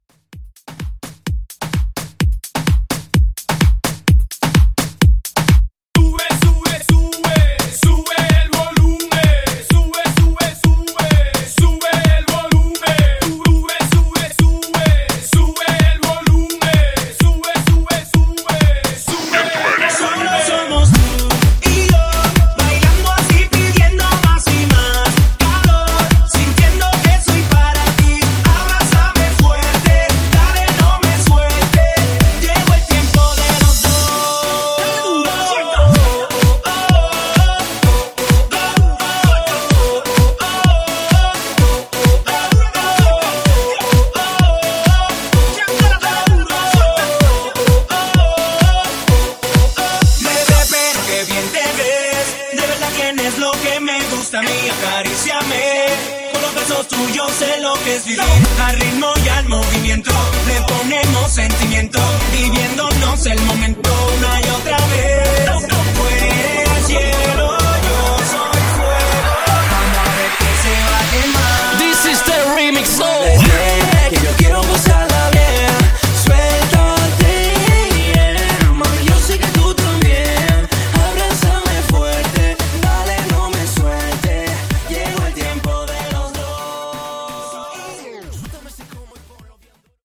Hype Moombah)Date Added